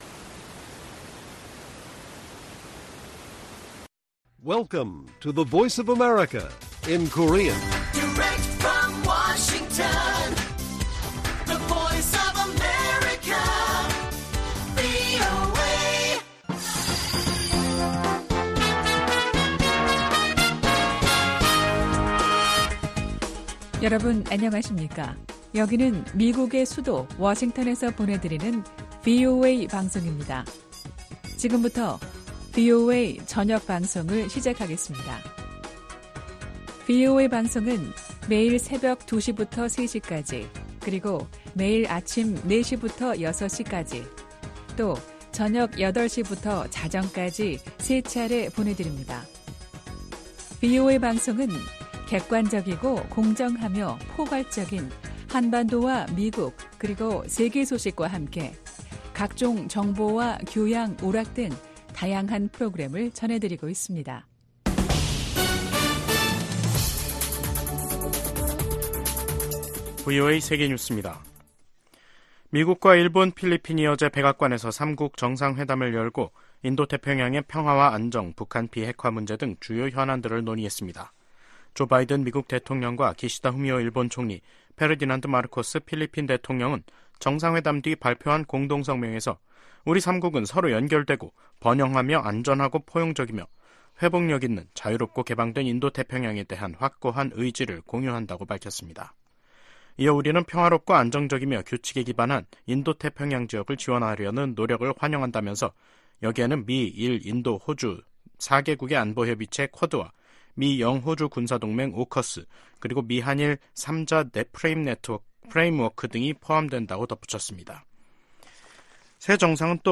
VOA 한국어 간판 뉴스 프로그램 '뉴스 투데이', 2024년 4월 12일 1부 방송입니다. 미국과 일본, 필리핀이 11일 워싱턴에서 사상 첫 3자 정상회의를 열고 남중국해와 북한 문제 등 역내 현안을 논의했습니다. 기시다 후미오 일본 총리는 미국 의회 상∙하원 합동회의 연설에서 현재 전 세계적으로 위협받고 있는 자유와 민주주의를 수호하려는 미국의 노력에 일본이 함께하겠다고 말했습니다.